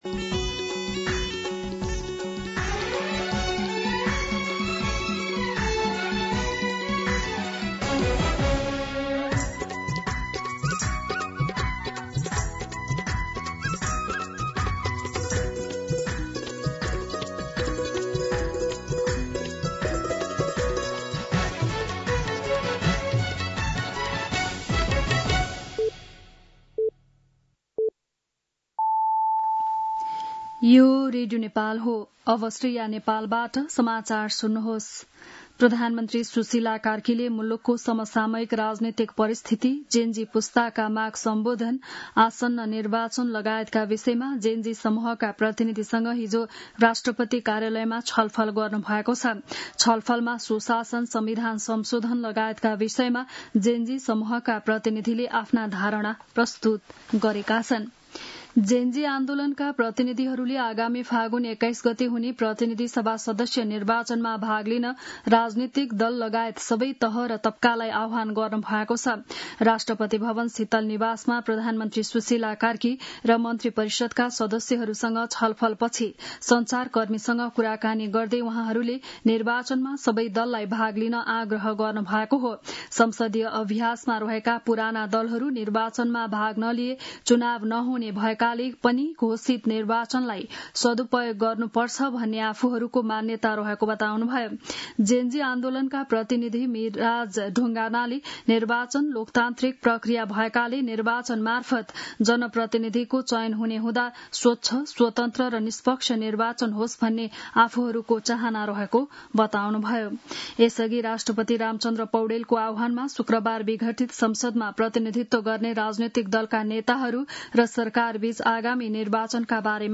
बिहान ११ बजेको नेपाली समाचार : २४ मंसिर , २०८२
11-am-Nepali-News-3.mp3